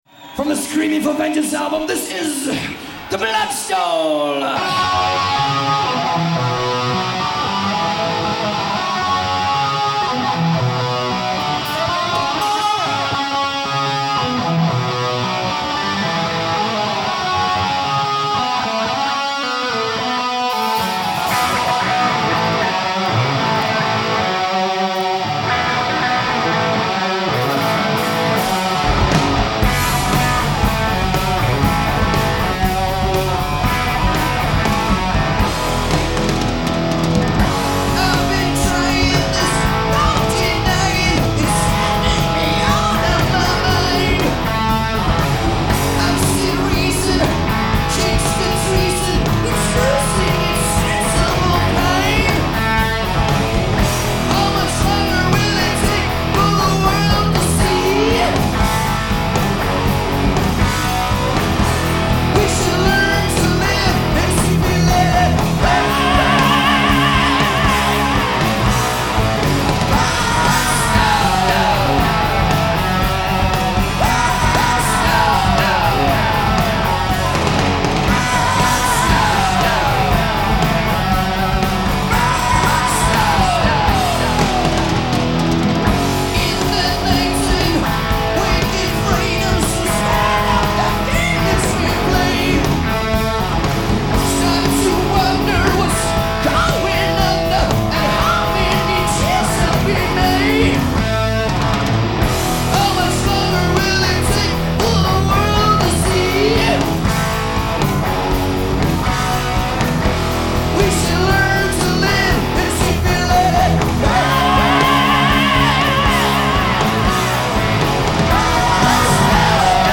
Genre : Metal
Live at The Omni, Atlanta, 1982